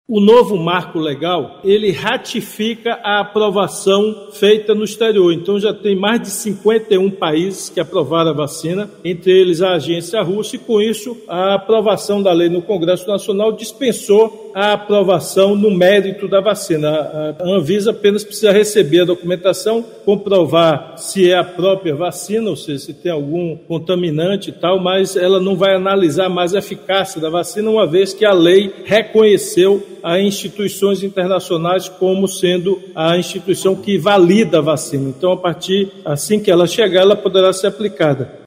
Rapidinha| Em transmissão ao vivo do programa Papo Correria, nesta quarta-feira (17), o governador Rui Costa explicou que a vacina Sputinik V poderá ser aplicada na população assim que chegar à Bahia. A eficácia da vacina não será mais julgada pela Agência Nacional de Vigilância Sanitária (Anvisa), já que a nova lei reconhece a validação…